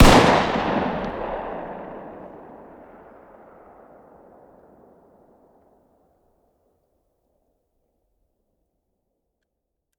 fire-dist-10x25-pist..>2024-09-10 22:10 508K